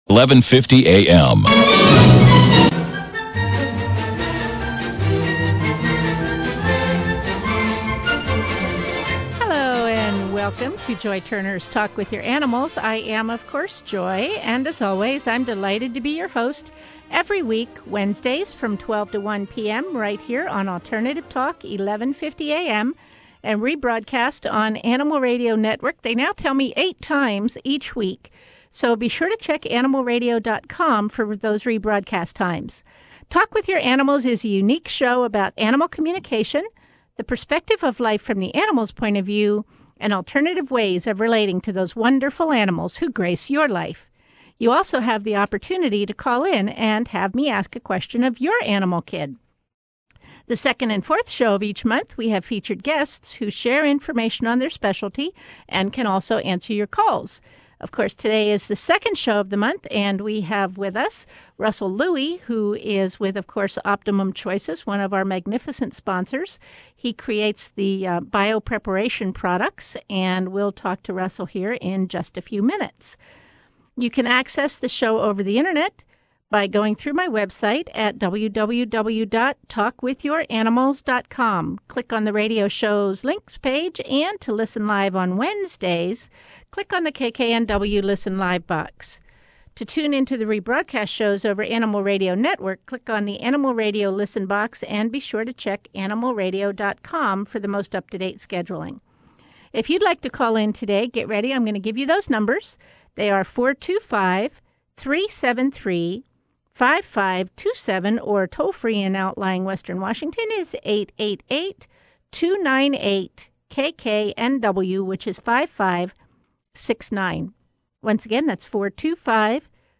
KKNW (1150 AM) Radio Show, Seattle